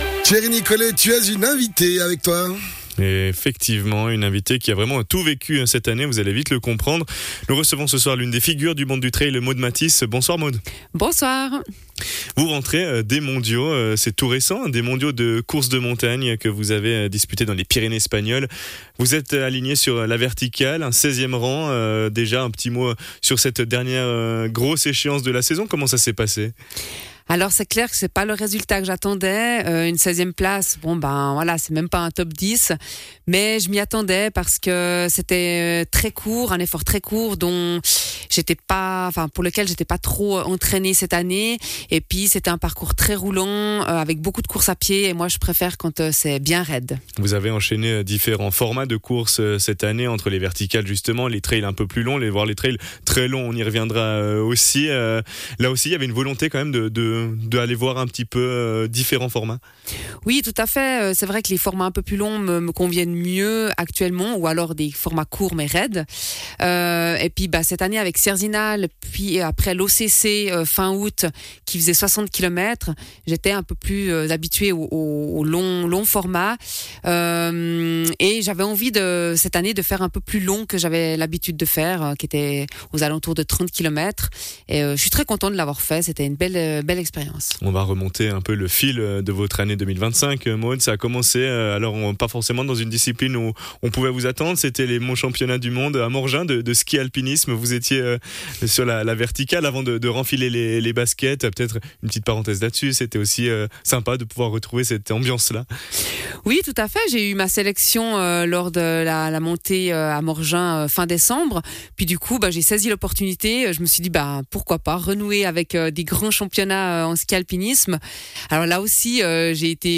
Intervenant(e) : Maude Mathys, athlète